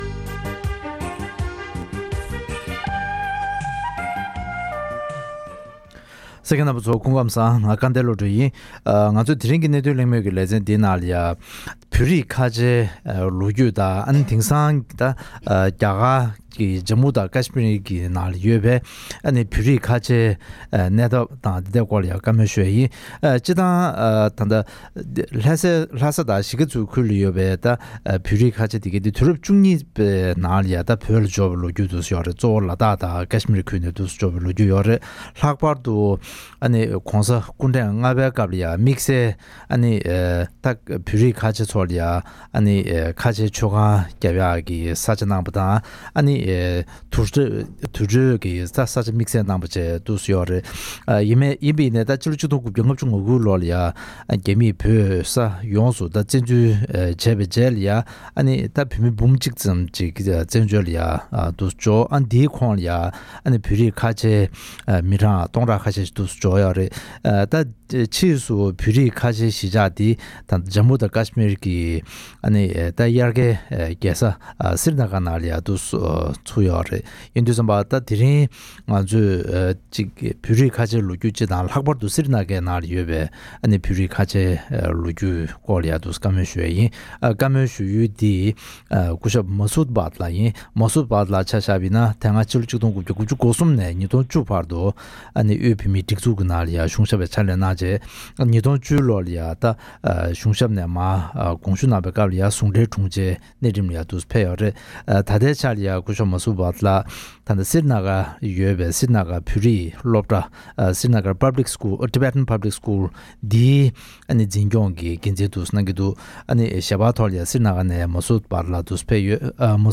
བོད་རིགས་ཁ་ཆེའི་ལོ་རྒྱུས་དང་དེང་སྐབས་རྒྱ་གར་གྱི་ཇ་མུ་དང་ཀཤ་མིར་ནང་ཡོད་པའི་བོད་རིགས་ཁ་ཆེའི་གནས་སྟངས་ཐད་གླེང་མོལ་གནང་བ།